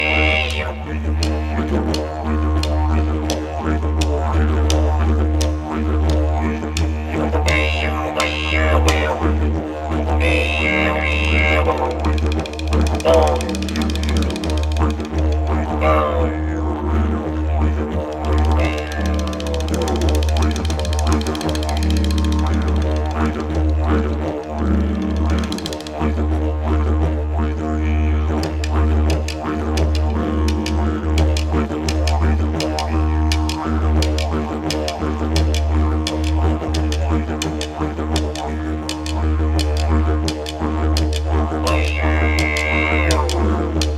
Звуки диджериду
Погрузитесь в мир аутентичных звуков диджериду — древнего духового инструмента аборигенов Австралии.